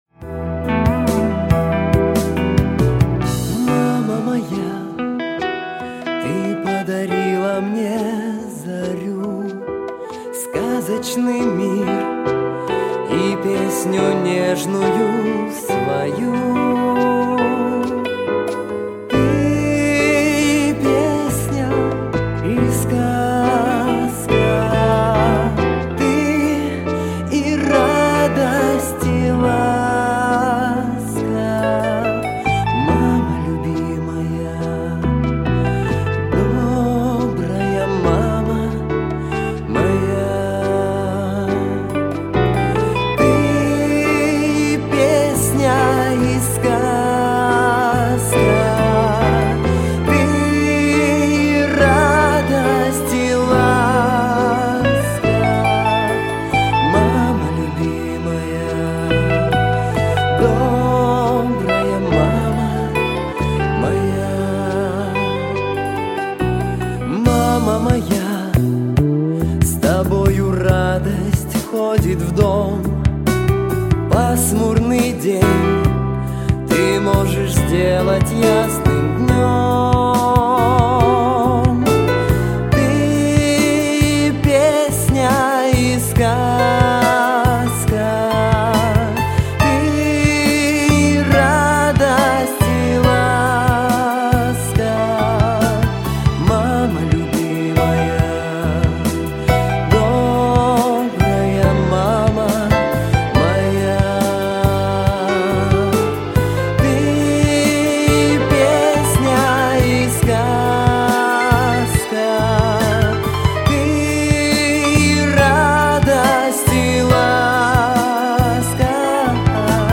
🎶 Детские песни / Песни про маму